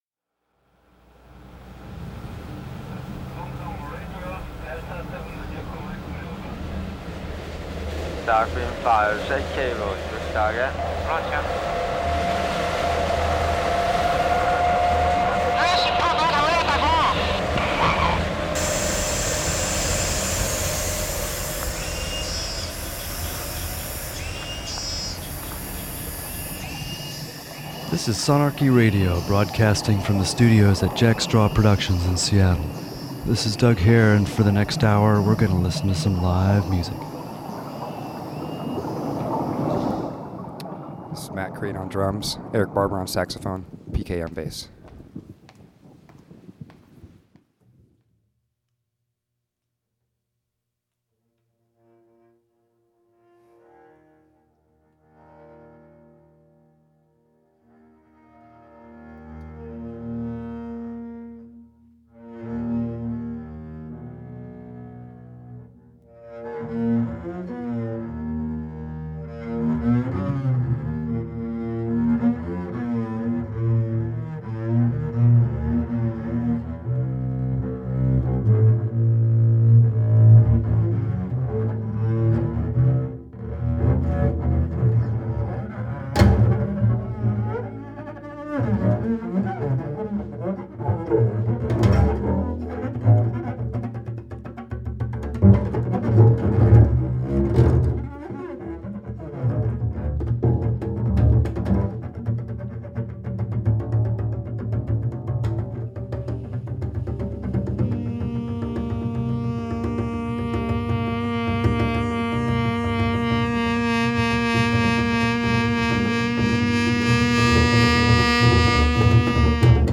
Drums and guitars, effects and amps, flutes and noise.